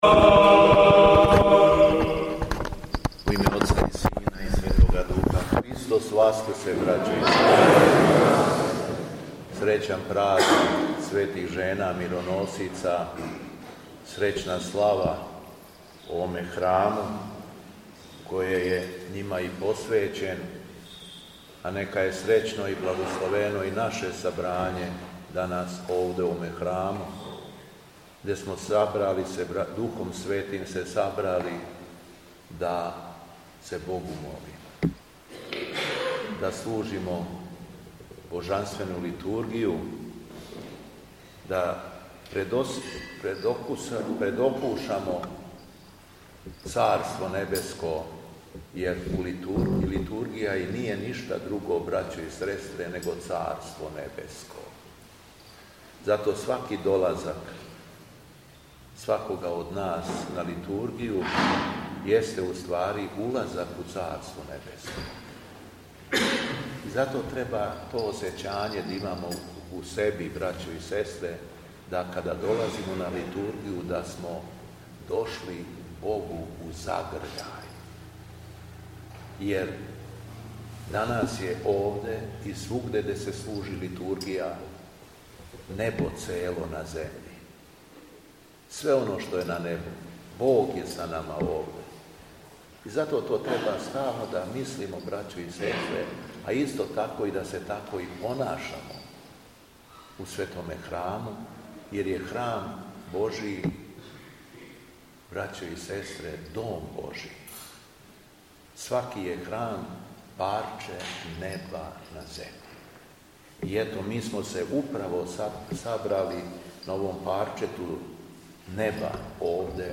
СВЕТЕ ЖЕНЕ МИРОНОСИЦЕ – ХРАМОВНА СЛАВА У ВИНЧИ
Беседа Његовог Високопреосвештенства Митрополита шумадијског г. Јована